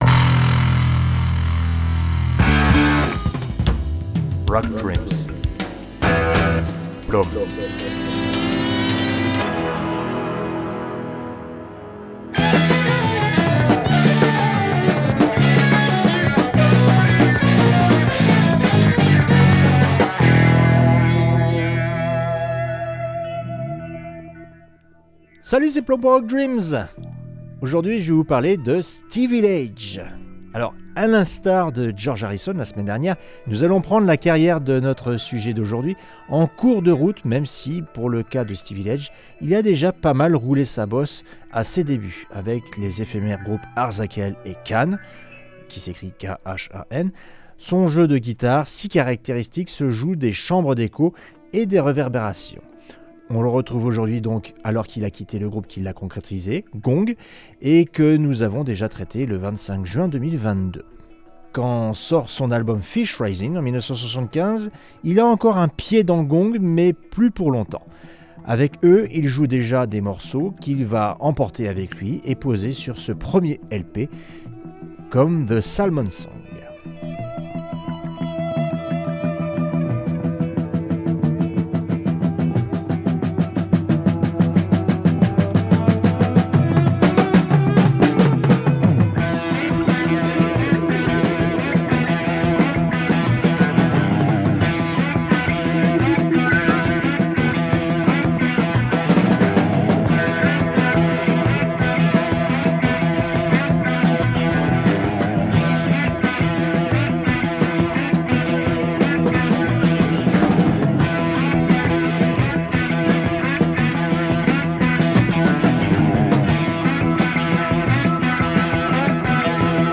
Space Rock